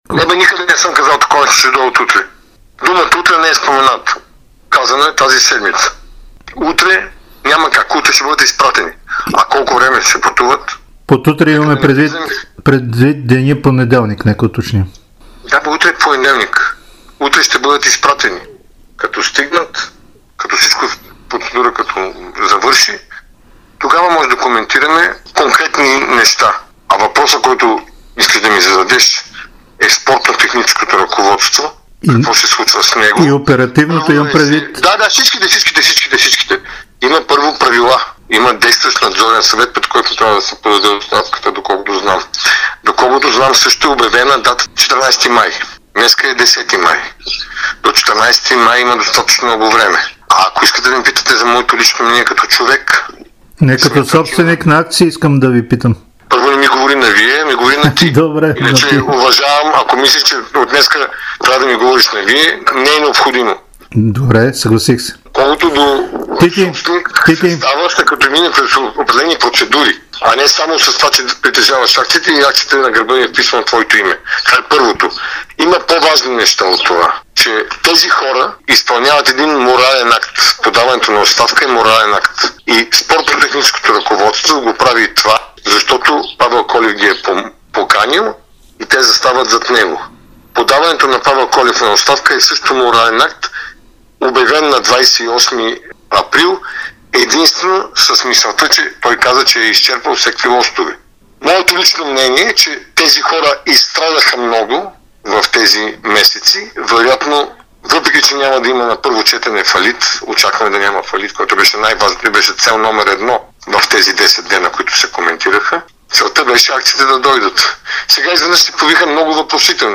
Тити Папазов даде дълго интервю за Дарик и dsport, в което заяви, че неговото лично мнение е, че Павел Колев и треньорският щаб на "сините" трябва да останат в клуба.